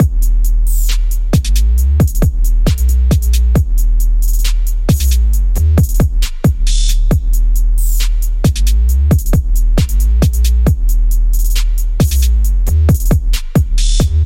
标签： 135 bpm Trap Loops Strings Loops 4.79 MB wav Key : D FL Studio
声道立体声